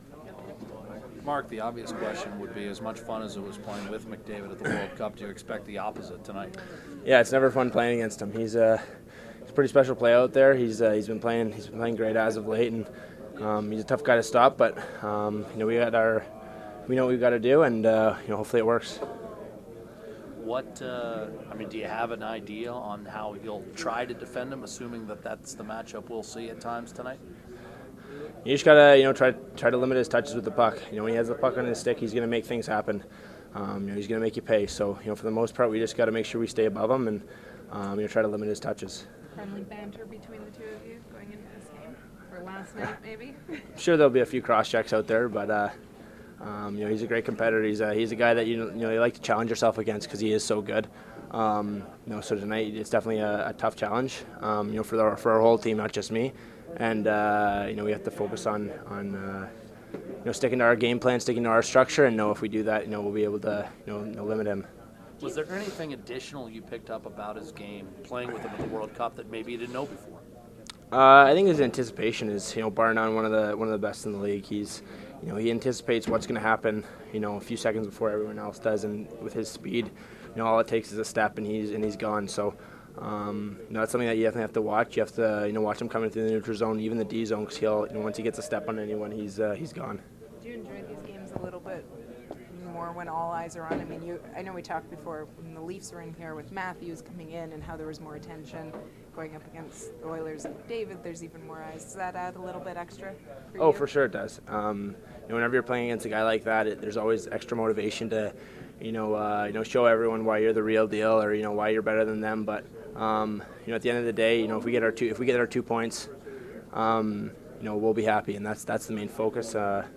Player pre-game audio:
All audio courtesy of TSN 1290 Winnipeg.